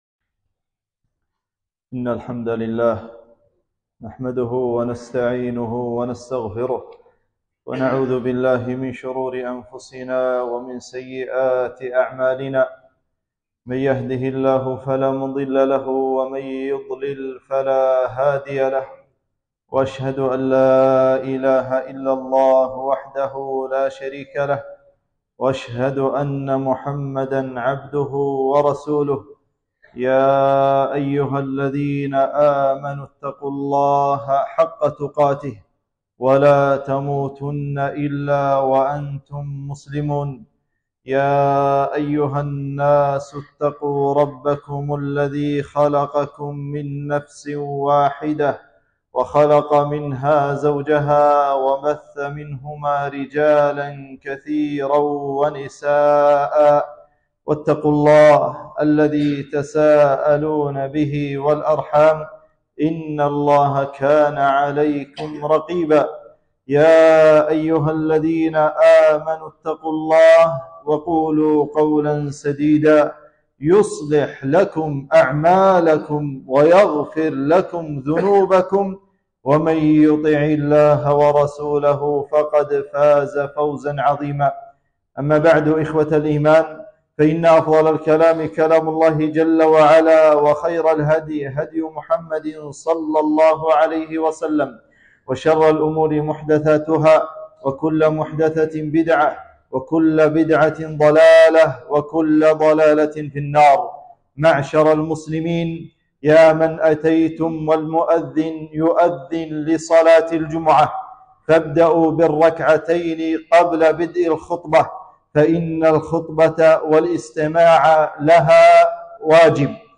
خطبة - فضائل أبي بكر الصديق رضي الله عنه